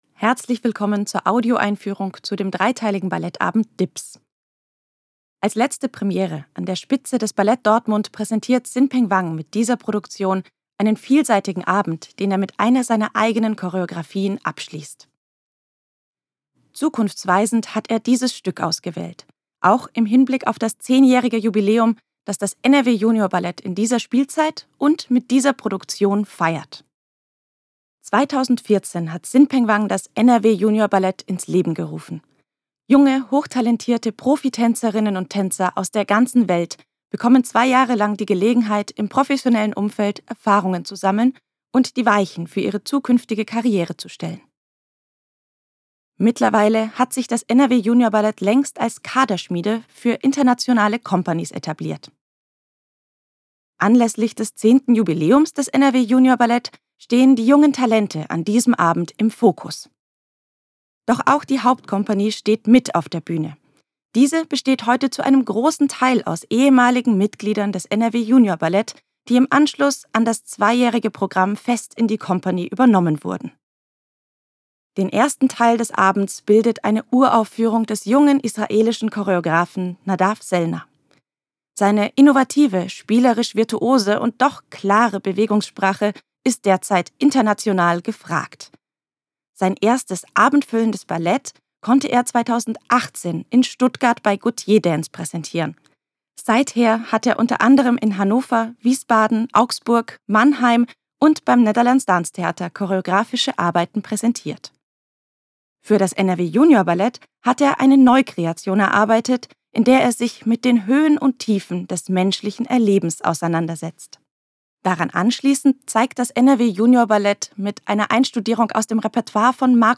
tdo_Audioeinfuehrung_Dips.mp3